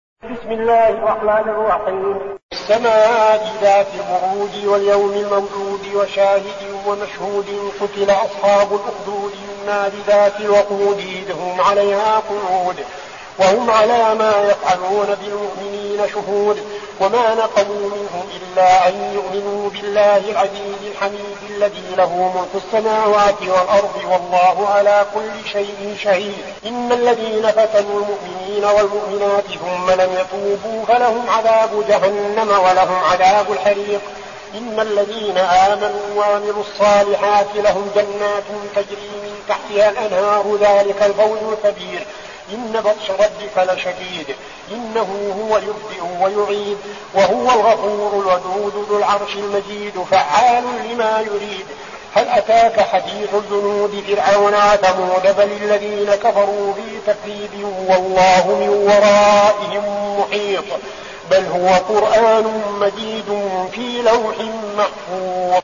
المكان: المسجد النبوي الشيخ: فضيلة الشيخ عبدالعزيز بن صالح فضيلة الشيخ عبدالعزيز بن صالح البروج